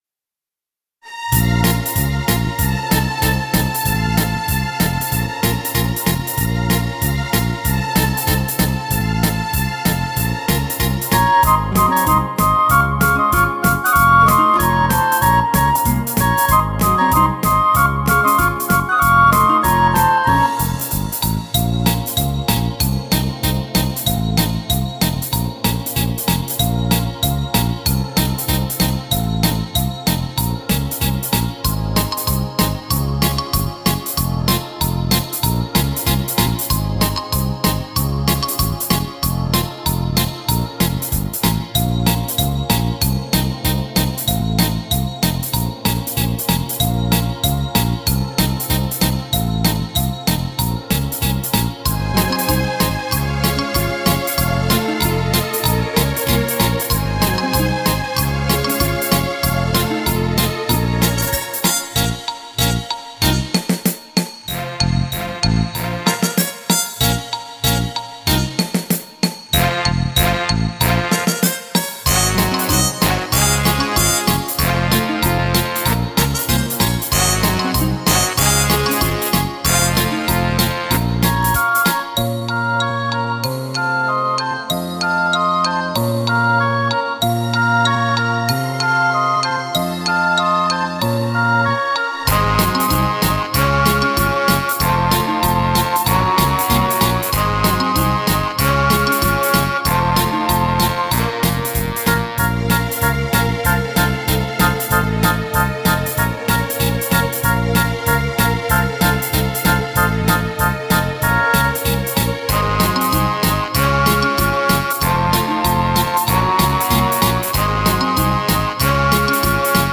Минус.